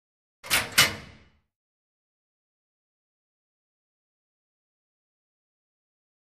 Locker Room; Metal Locker Door Open.